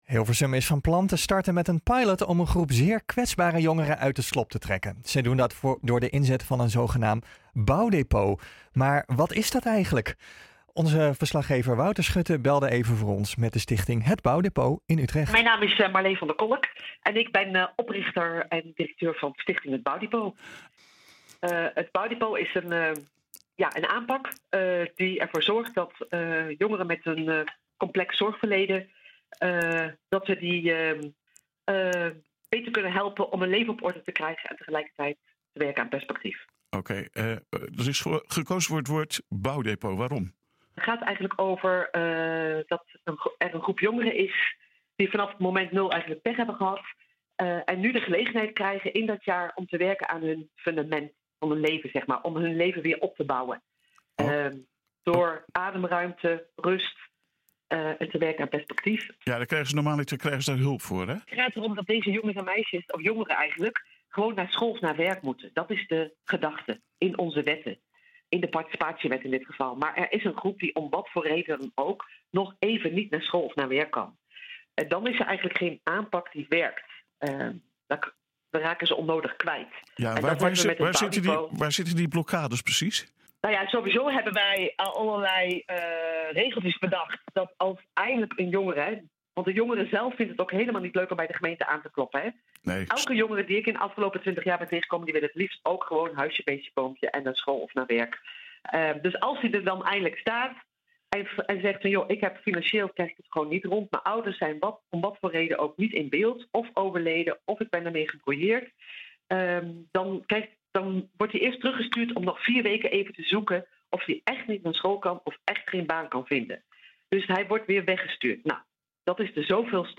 belde even voor ons met de Stichting “Het Bouwdepot “ in Utrecht.